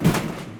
WRECK03.WAV